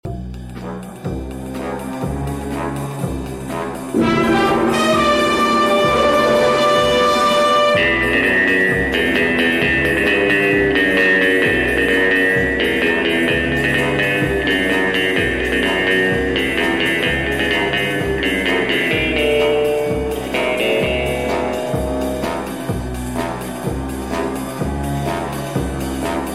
Kategorien Filmmusik